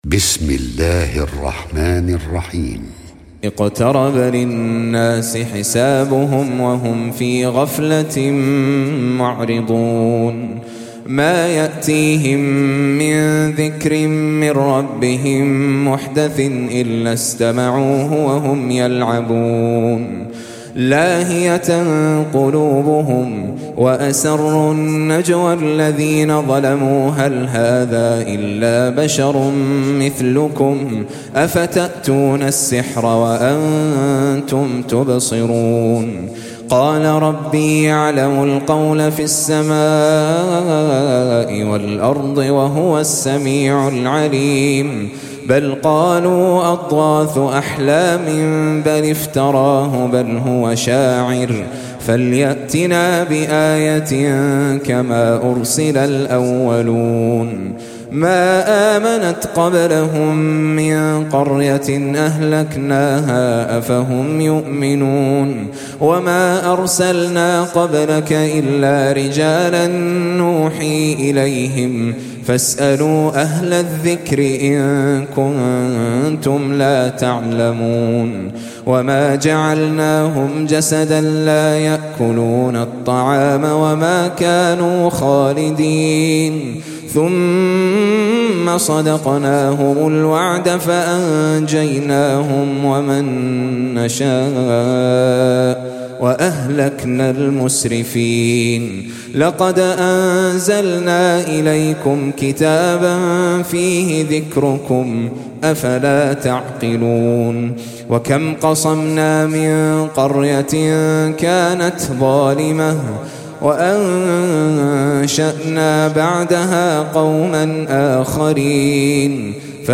21. Surah Al-Anbiy�' سورة الأنبياء Audio Quran Tajweed Recitation
حفص عن عاصم Hafs for Assem
Surah Sequence تتابع السورة Download Surah حمّل السورة Reciting Murattalah Audio for 21.